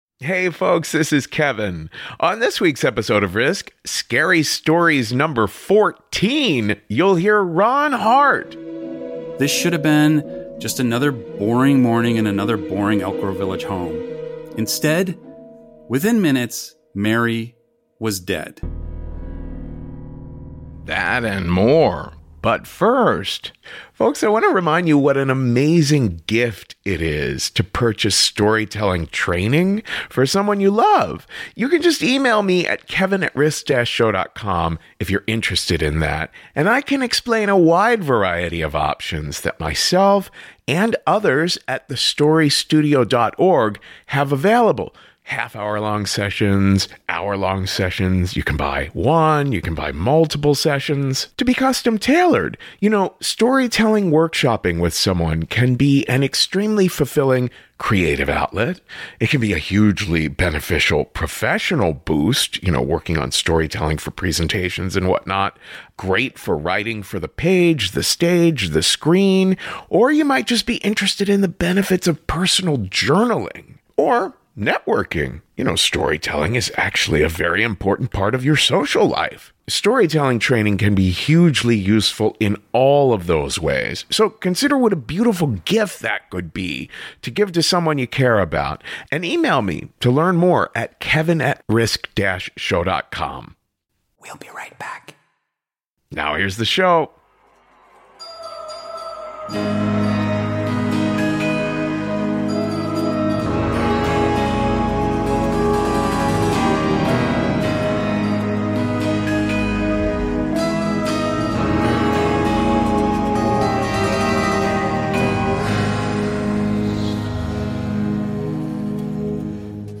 Our 14th annual Halloween special filled with scary true stories, spooky songs and frightful surprises.